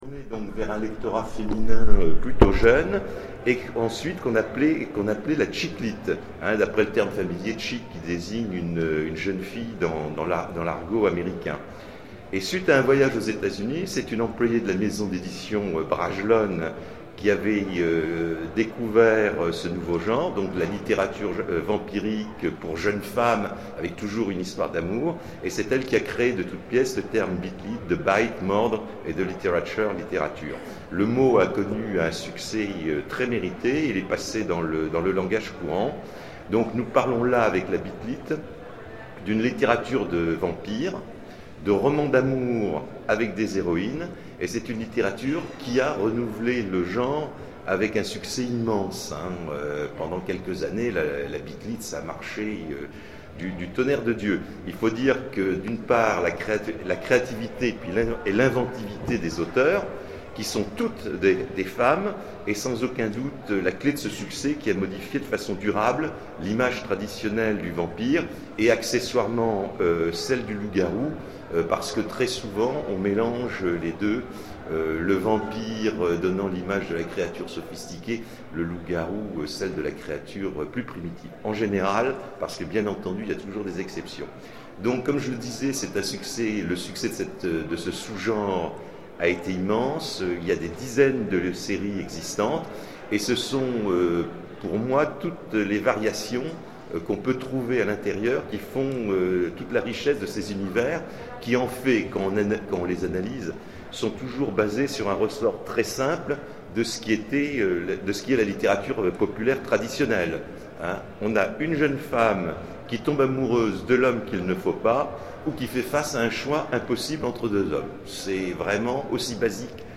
Zone Franche 2013 : Conférence Bit lit, urban fantasy... le renouvellement du genre fantastique.